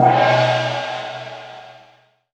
TAM TAM   -L.WAV